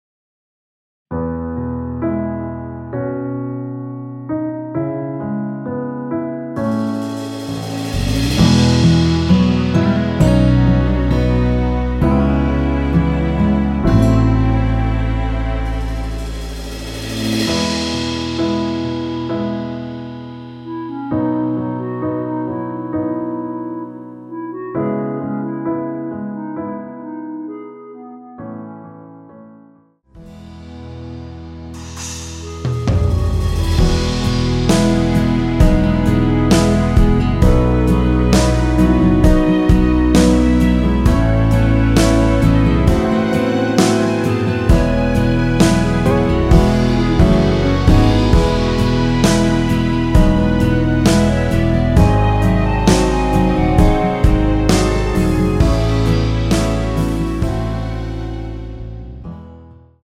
원키에서(-3)내린 멜로디 포함된 MR입니다.
멜로디 MR이라고 합니다.
앞부분30초, 뒷부분30초씩 편집해서 올려 드리고 있습니다.
중간에 음이 끈어지고 다시 나오는 이유는